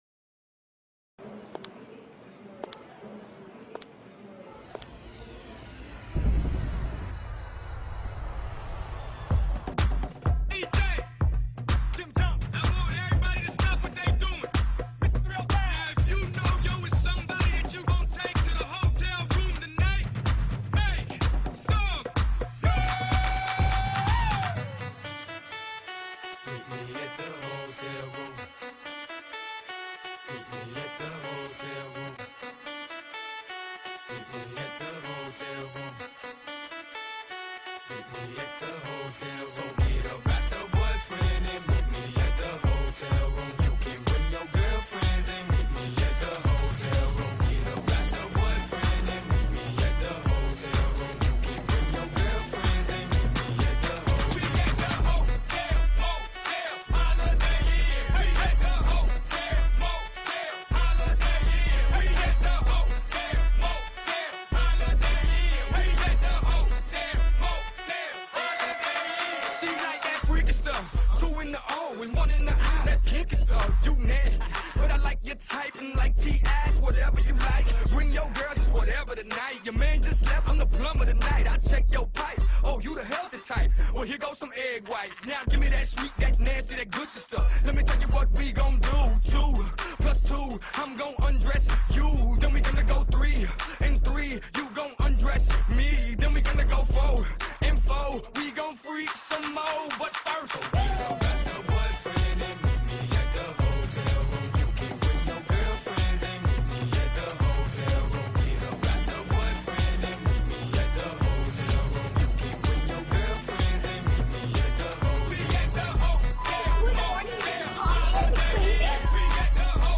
Category: HipHop Music